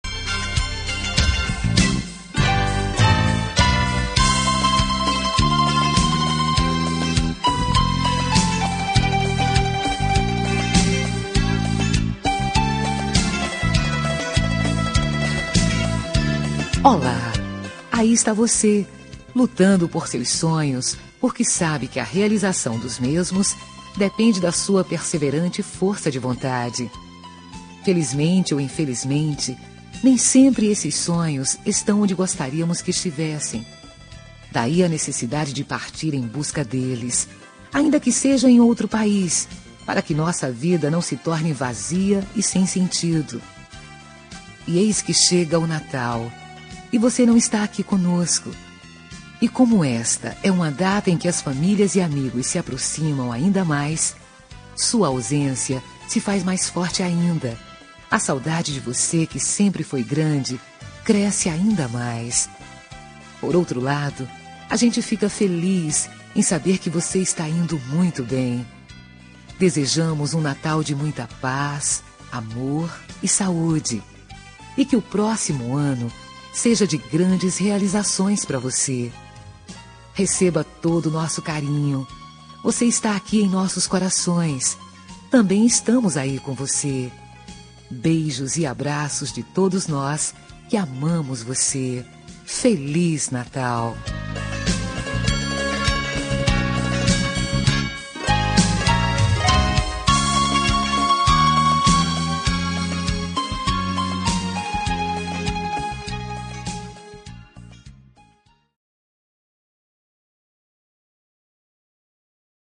Natal Pessoa Especial – Voz Feminina – Cód: 348994 – Distante